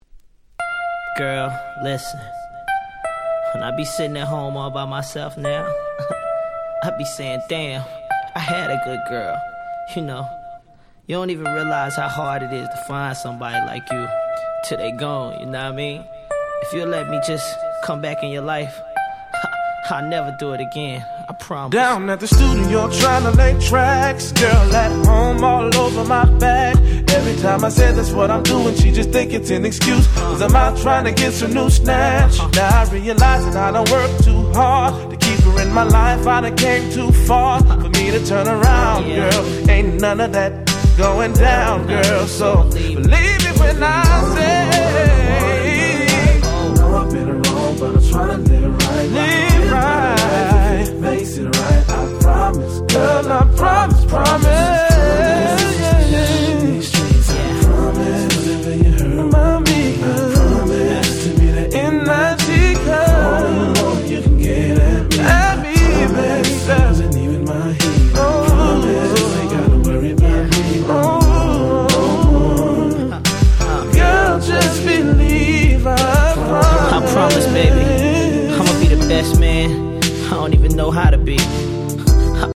99' Super Hit R&B / Slow Jam !!